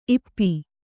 ♪ ippi